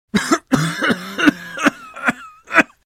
cough3.wav